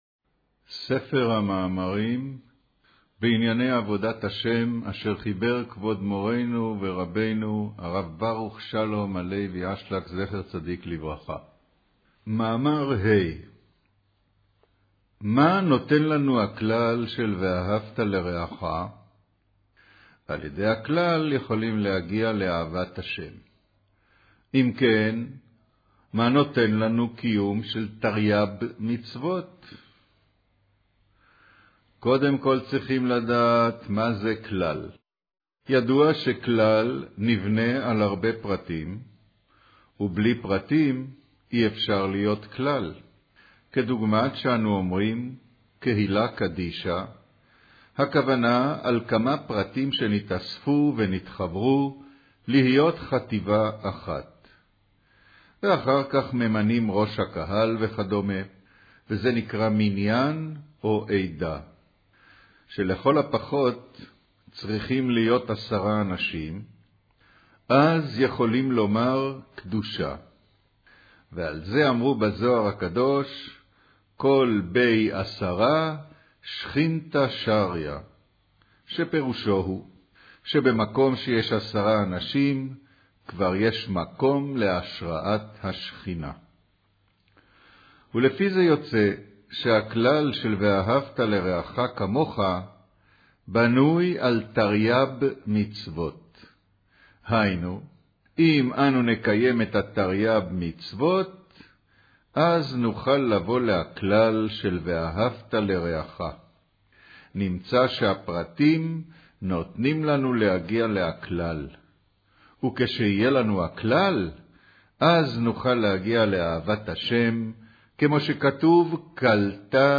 קריינות